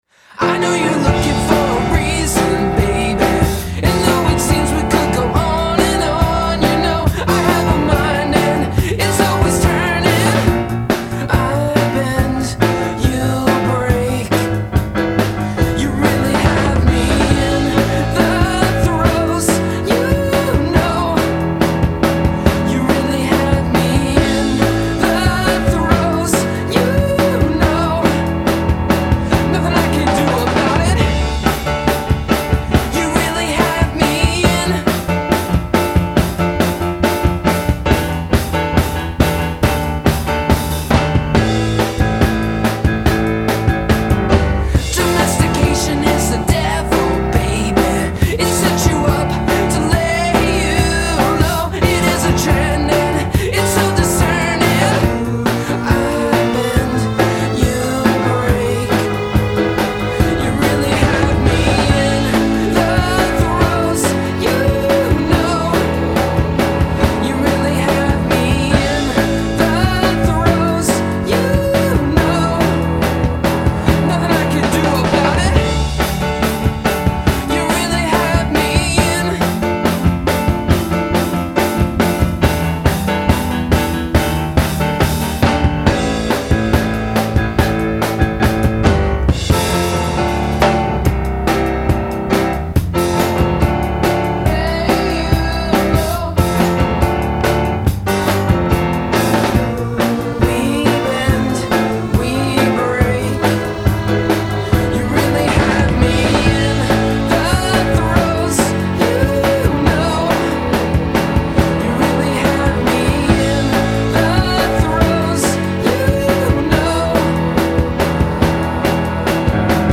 They’re a mixture of Elton John, Billy Joel and Queen.